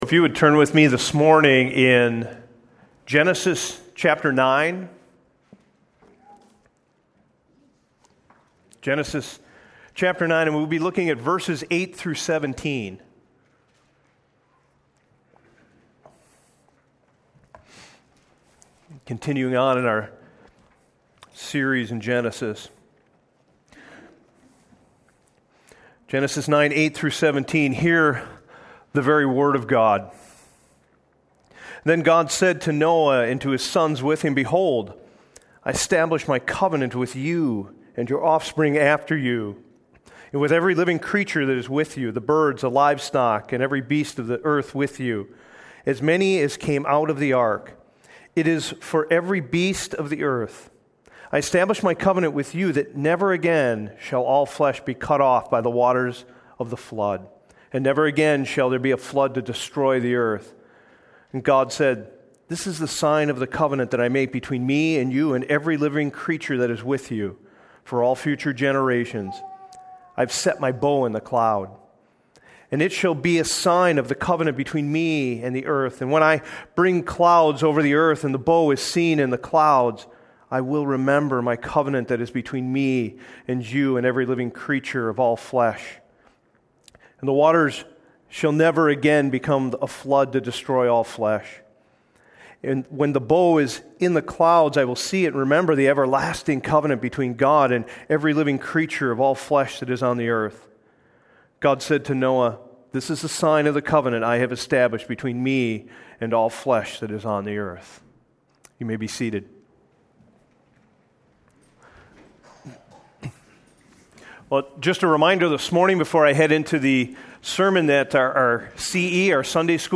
Sermons - La Crescent Evangelical Free Church